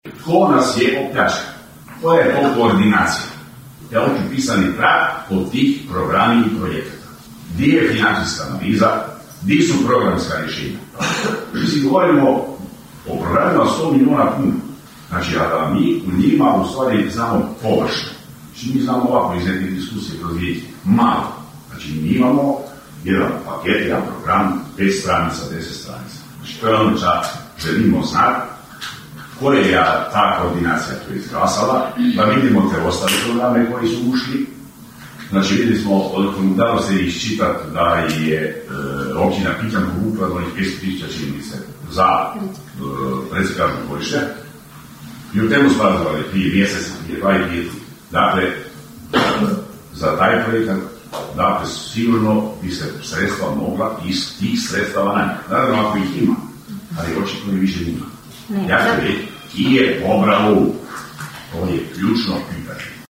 Proračun Općine Kršan za iduću godinu planiran je u visini od 13.807,882 eura, rečeno je sinoć na sjednici Općinskog vijeća na prvom čitanju proračuna.
Nezavisni vijećnik Valdi Runko se upitao: (